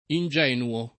[ in J$ nuo ]